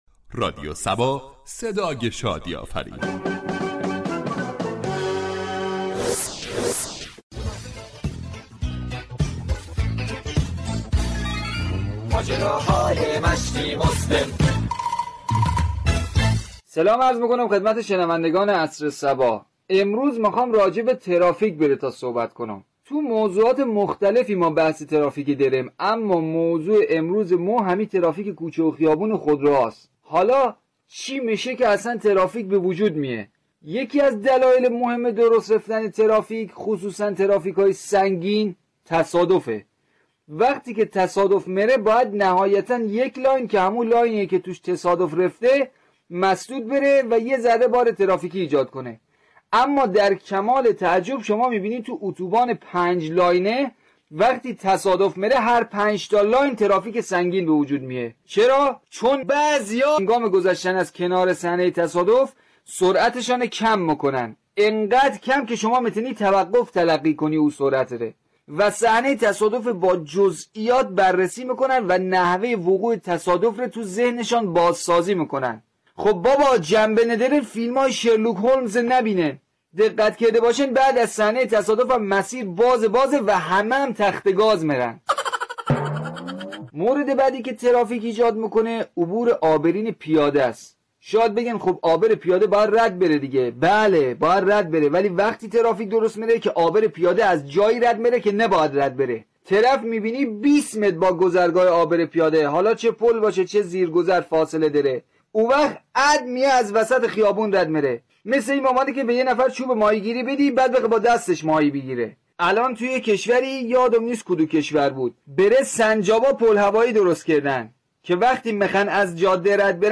برنامه زنده "عصر صبا " مجله ای عصرگاهی است كه با موضوعاتی از قبیل اجتماعی و حقوق شهروندی هر روز ساعت 16تقدیم شنوندگان رادیو صبا میشود .
در بخش نمایشی این برنامه با عنوان "ماجراهای مشتی مسلم " به موضوع ترافیك پرداخته شده است،در ادامه شنونده این بخش باشید.